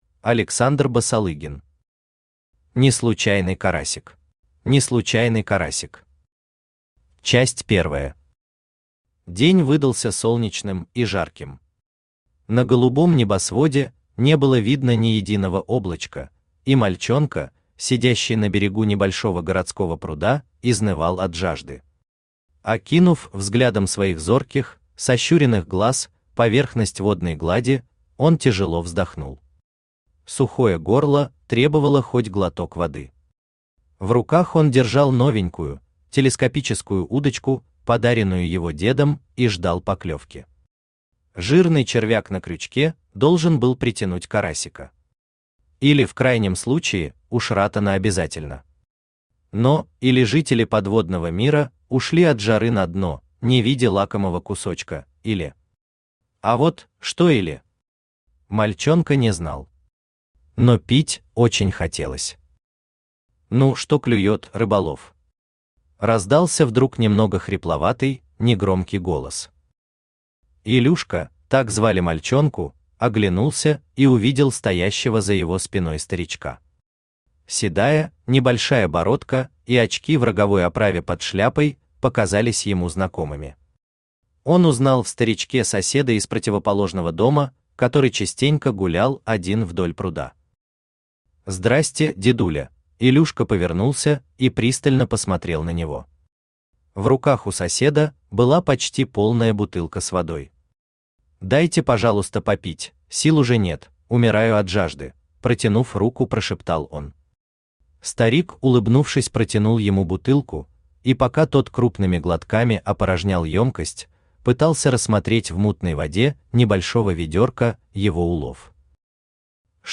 Аудиокнига «Неслучайный» карасик | Библиотека аудиокниг
Aудиокнига «Неслучайный» карасик Автор Александр Аркадьевич Басалыгин Читает аудиокнигу Авточтец ЛитРес.